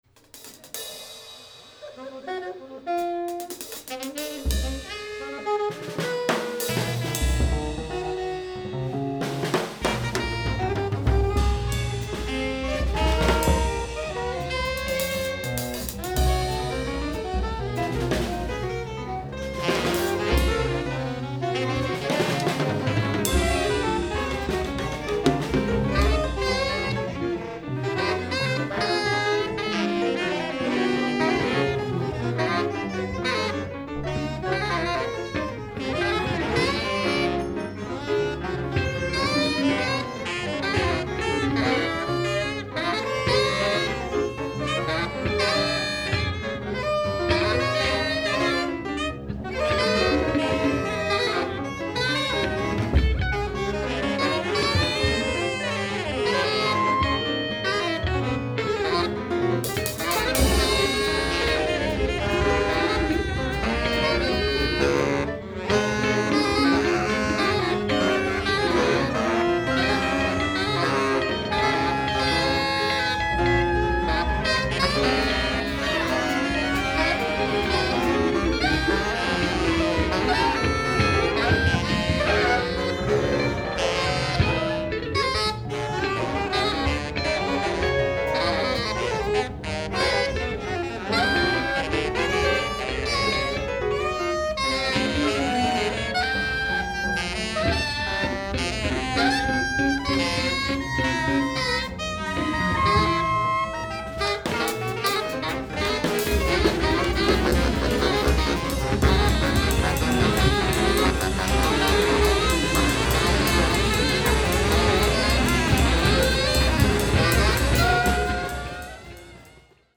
Volume 1, Cafe OTO 9th July 2022